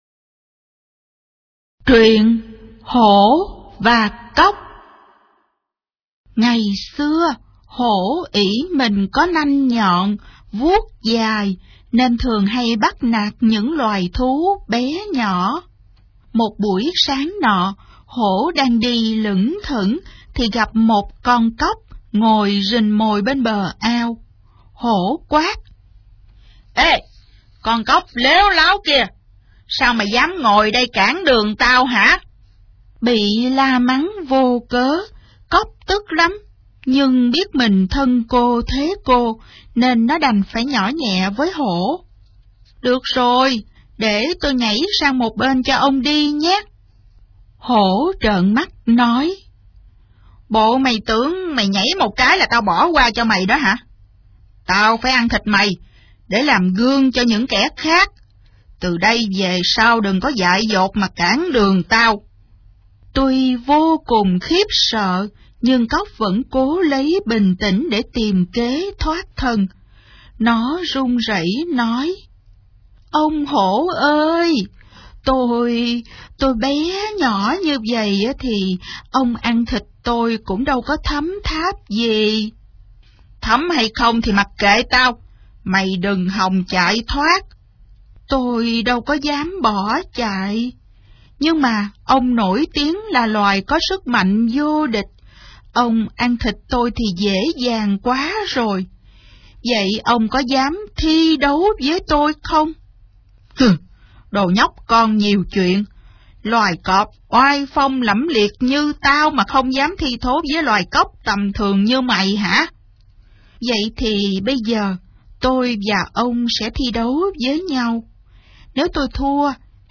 Sách nói | Hổ Và Cóc